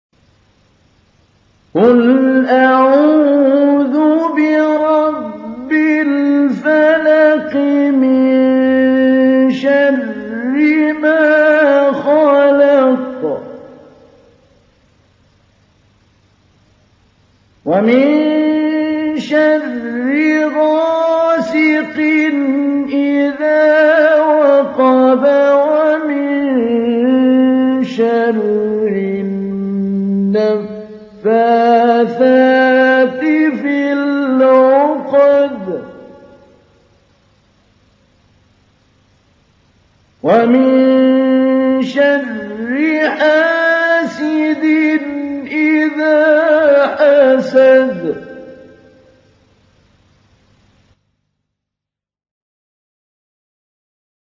تحميل : 113. سورة الفلق / القارئ محمود علي البنا / القرآن الكريم / موقع يا حسين